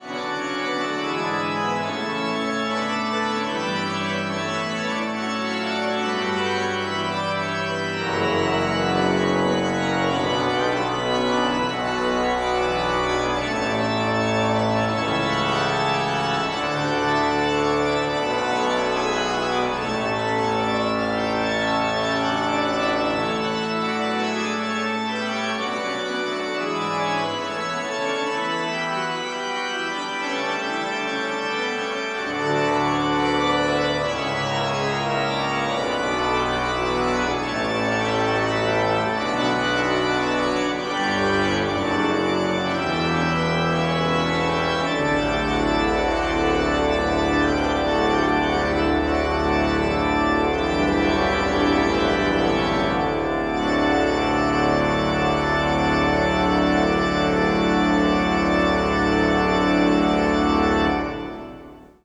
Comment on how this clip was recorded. Tetrahedral Ambisonic Microphone Recorded December 12, 2009, in the Bates Recital Hall at the Butler School of Music of the University of Texas at Austin.